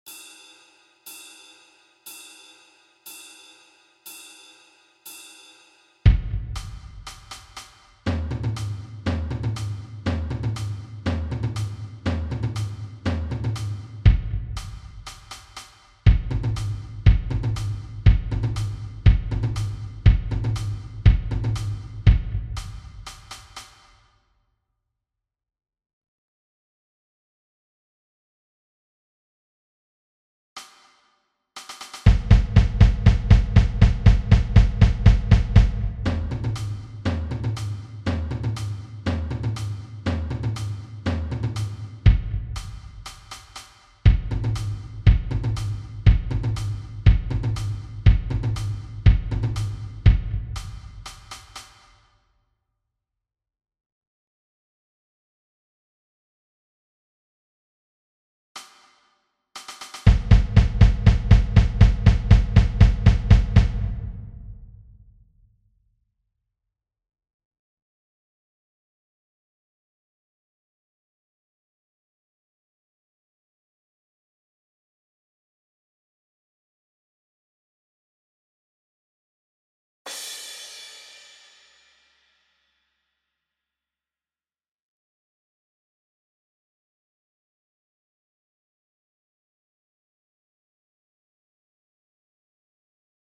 Structure classique Soul, mais le theme sonne plus "Cajun".
January_1st_Parts-Batterie.mp3